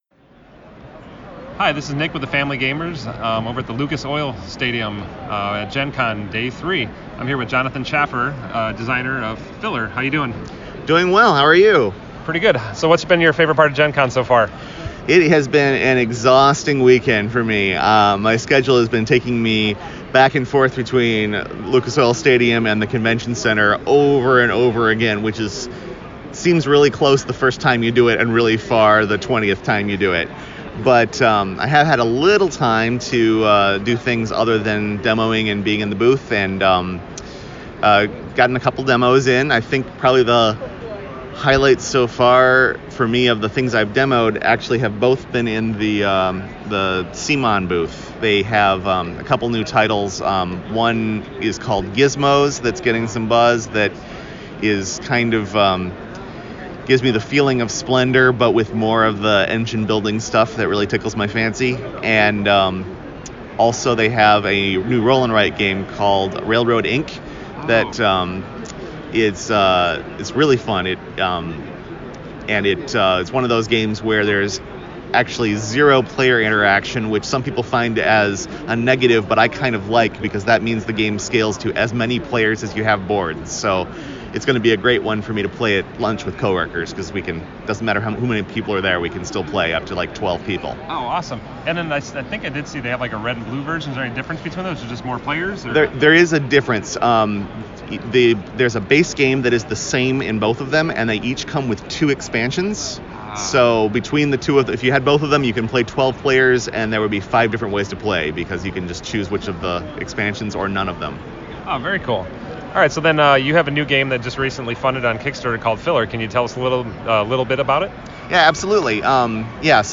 Listen to more short interviews .